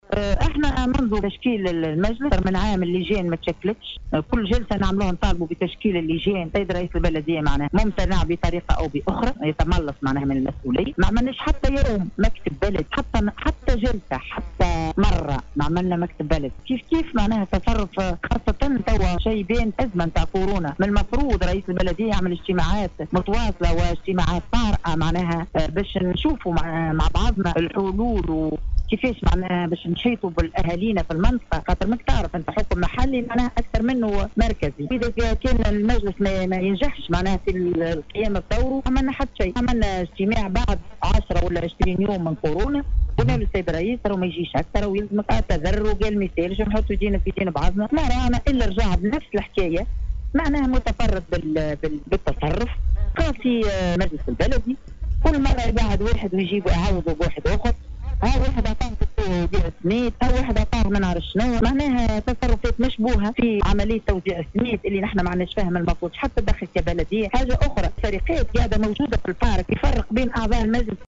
وأوضحت ناجية خليفي عضو المجلس البلدي خلال تدخلها اليوم الجمعة عبر موجات اذاعة السيليوم أنّ أسباب الإستقالة تعود إلى صعوبة التعامل مع رئيس البلدية وتعمّده تاخير تشكيل اللجان وإنفراده في أخذ القرار ات دون المرور عبر المجلس البلدي بالإضافة الى فشله في التعامل مع أزمة كورونا, حسب تعبيرها.